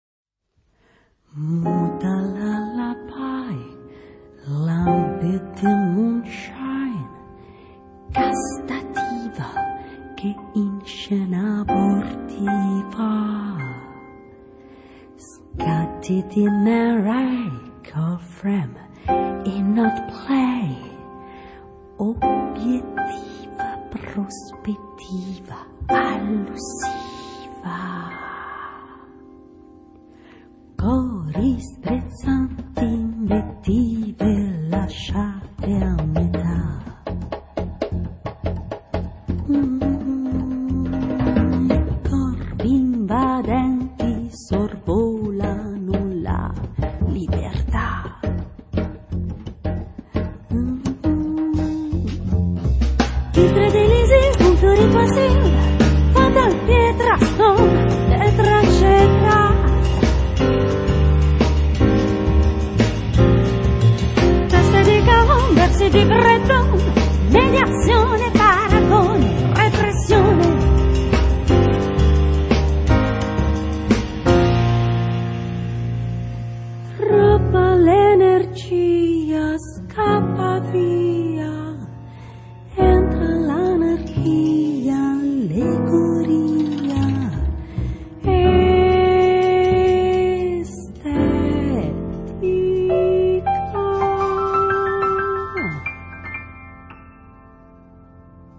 晚安曲依舊是週末的爵士樂。
獨特的嗓音，相當迷人。